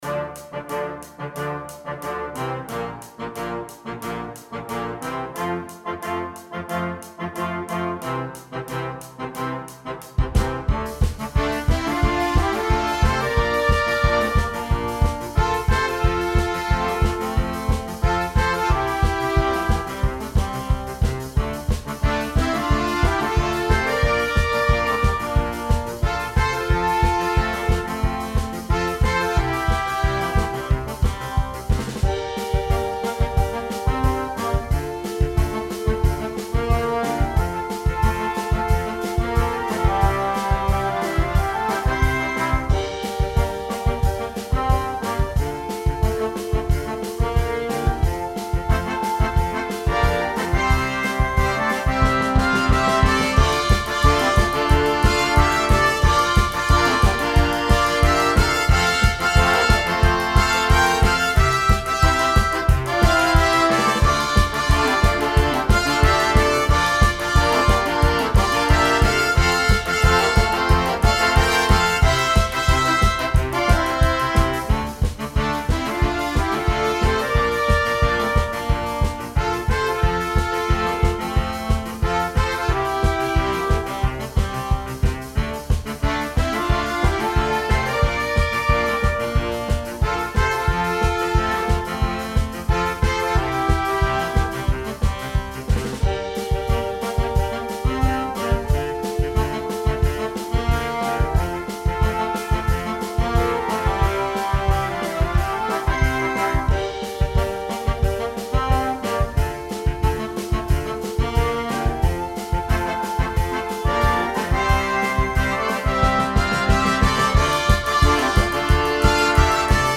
Catégorie : Arrangement musical
Type de formation : Banda
Pré-écoute non téléchargeable · qualité réduite